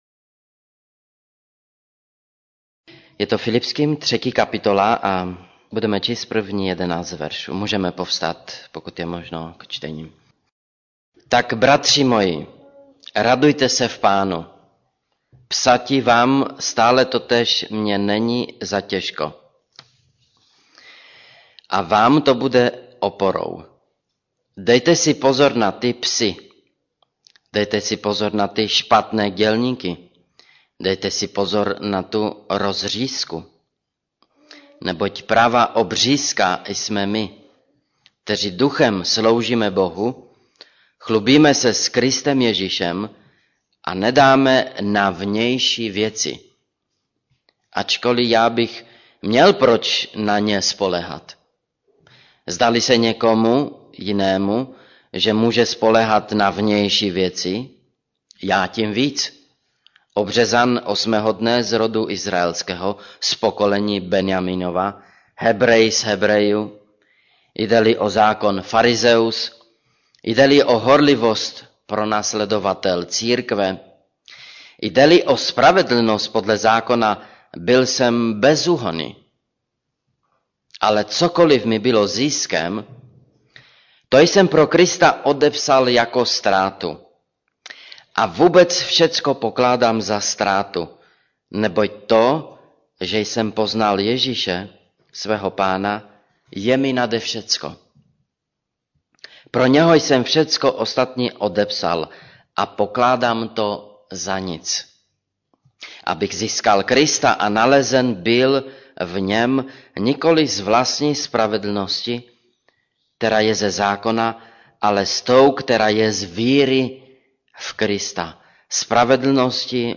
Pramen opravdové radosti Kategorie: Kázání MP3 Zobrazení: 3253 Jak žít radostný život 5.